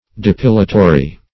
Depilatory \De*pil"a*to*ry\, a. [Cf. F. d['e]pilatoire.]